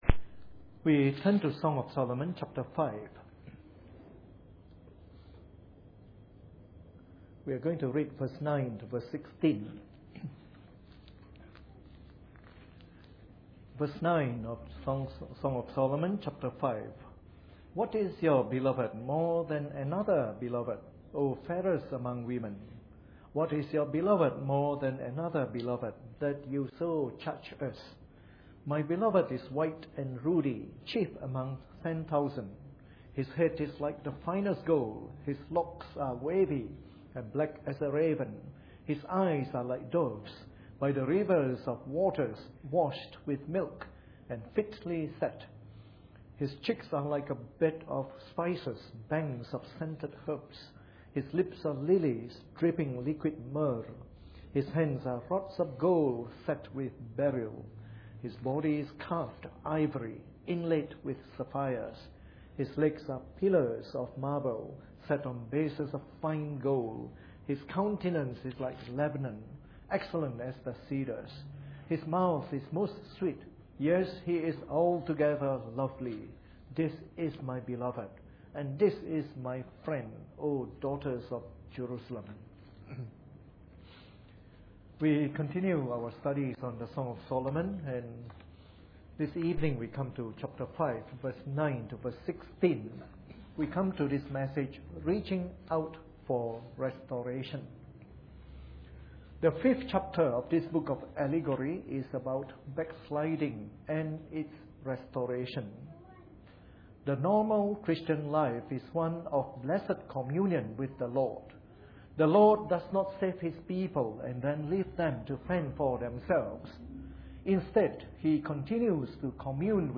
Preached on the 11th of January 2012 during the Bible Study from our new series on the Song of Solomon.